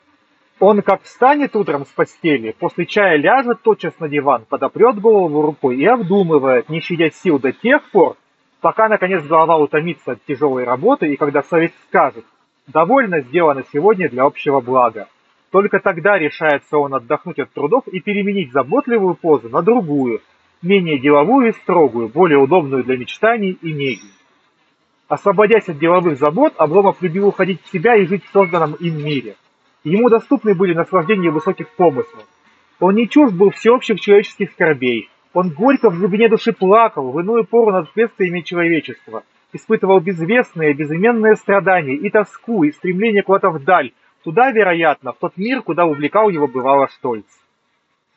3-Micnoise.mp3